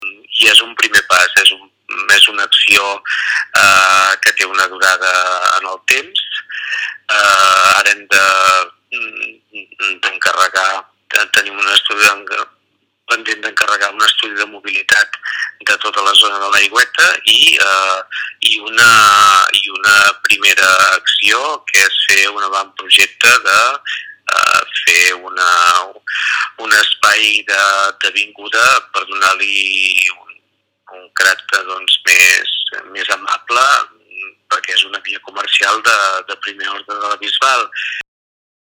L'alcalde del municipi, Enric Marquès, ha explicat a Ràdio Capital que es tracta d'un projecte impulsat pel Consell d'Iniciatives Econòmiques de la Bisbal que té per objectiu "fer de l'Aigüeta un carrer comercial més agradable"